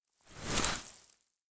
Free Frost Mage - SFX
ice_teleport_out_04.wav